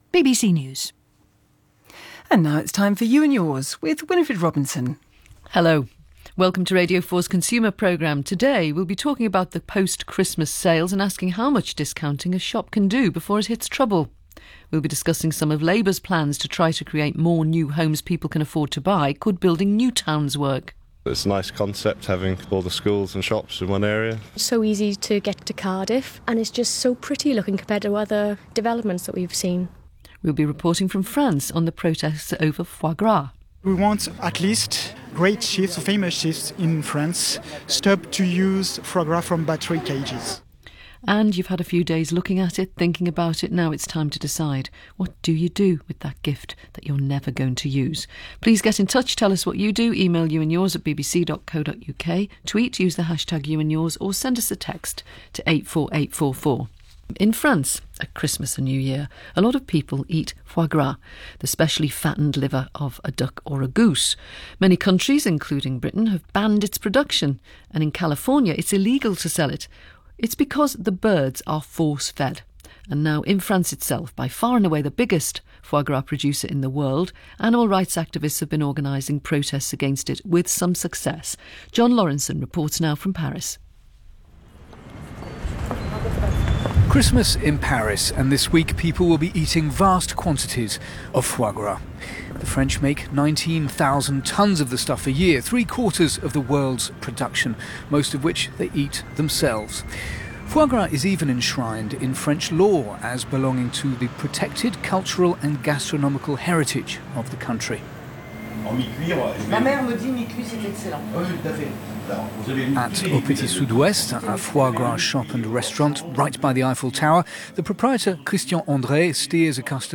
Reportage sur le foie gras en France, interview de L214 lors de l’action devant l’école de cuisine d’Alain Ducasse », BBC Radio 4, 27 décembre 2013